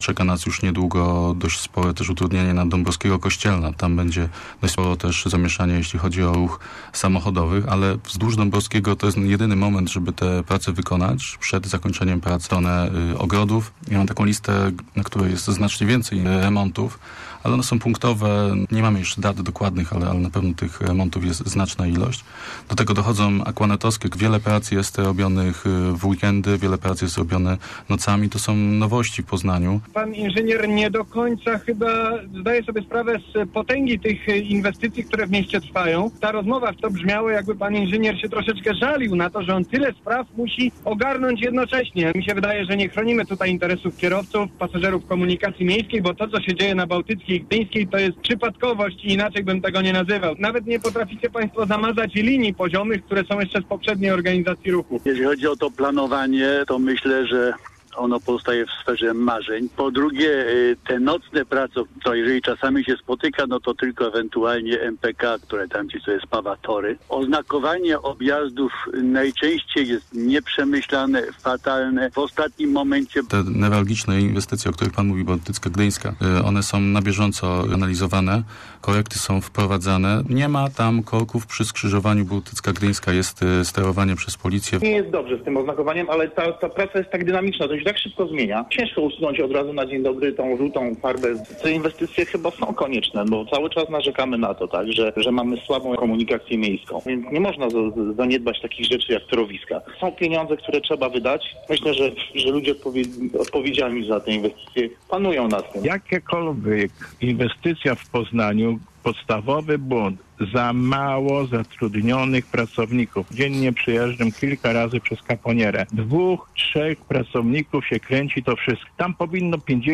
Naszym gościem był Miejski Inżynier Ruchu, Łukasz Dondajewski, który zatwierdza każdy remont w Poznaniu i odpowiada za organizację ruchu w mieście.
bd58zmp236aubwr_skrot-audycji-o-remontach.mp3